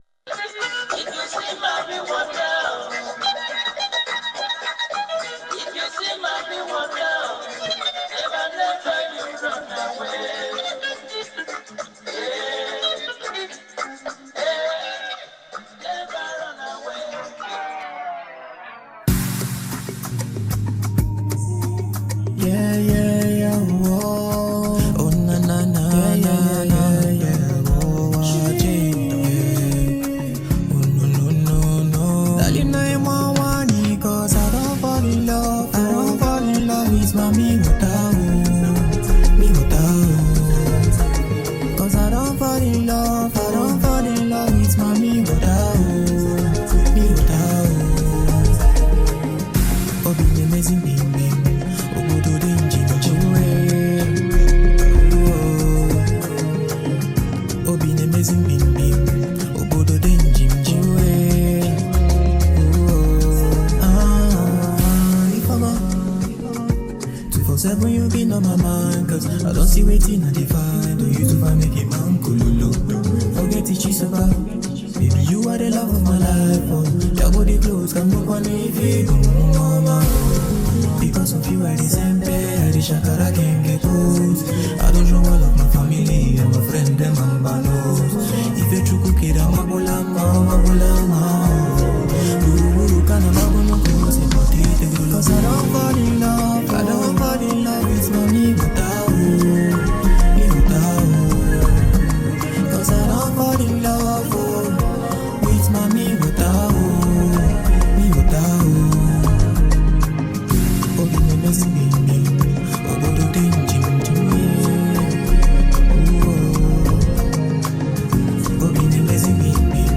Afrobeats
the track boasts lush instrumentals and finely crafted beats
rich and emotive vocals